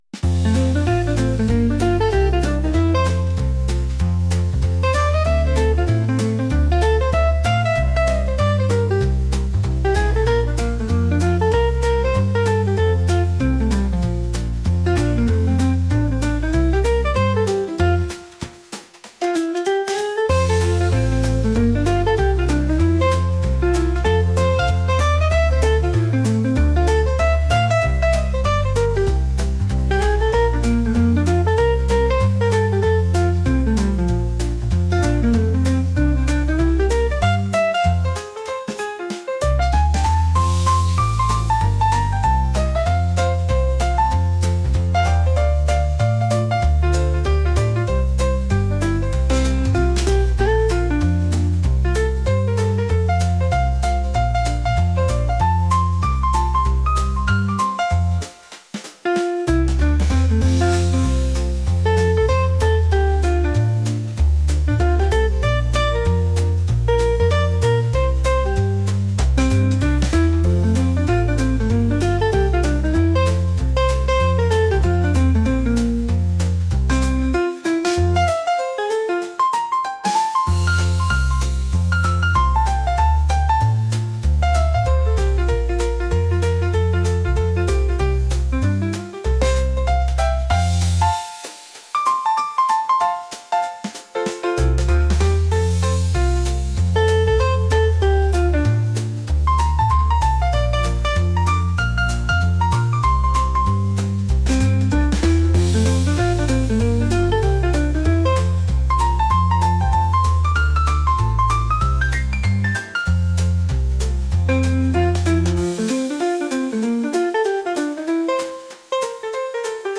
jazz | upbeat